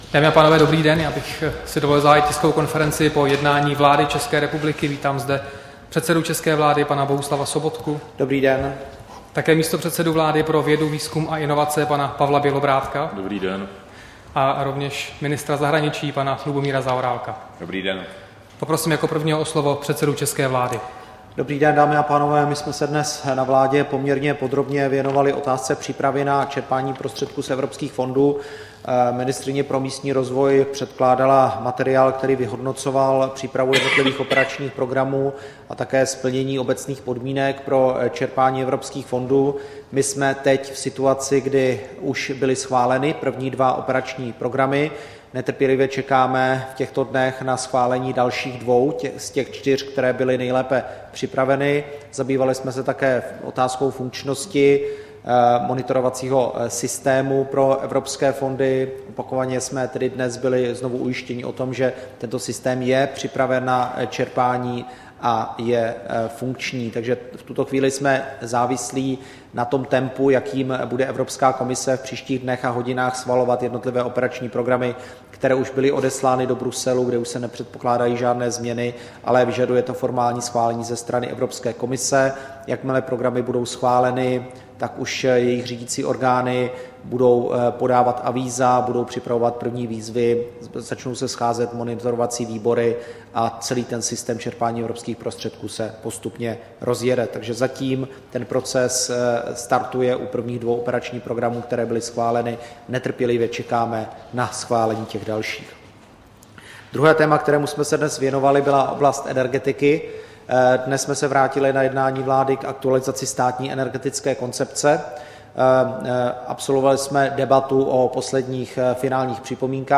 Tisková konference po jednání vlády, 6. května 2015